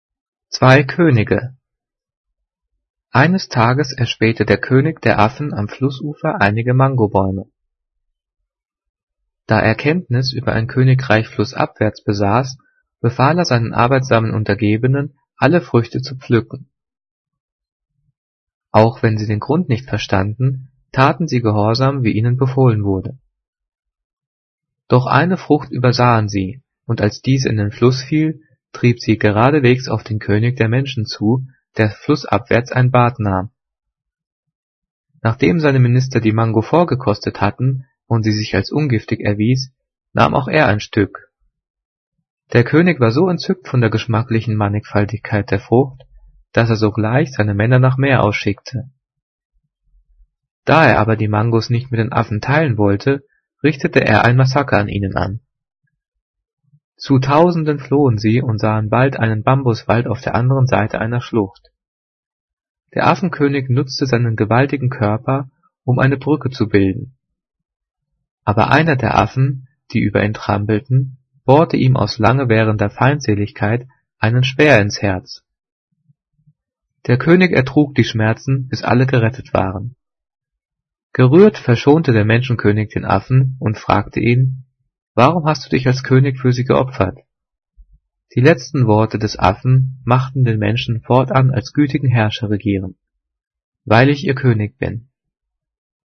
Gelesen: